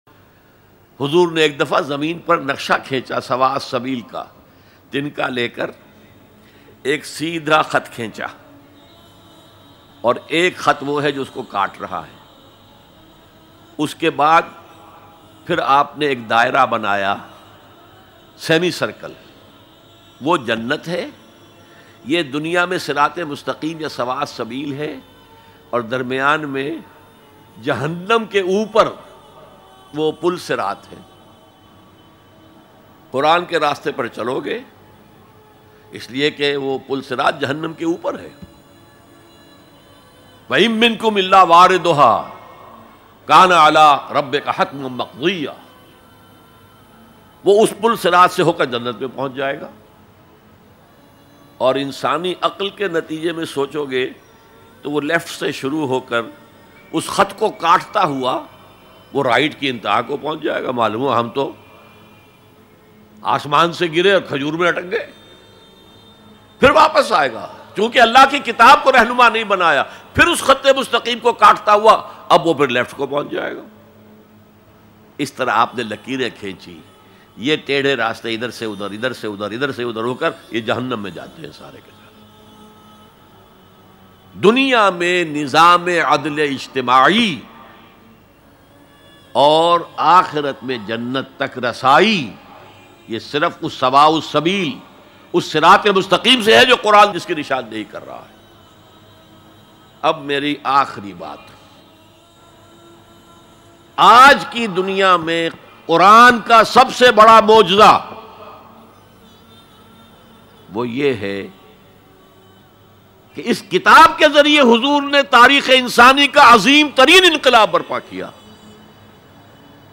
Quran Ki Azmat Dr Israr Ahmad Bayan MP3 Download